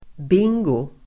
Uttal: [b'ing:go]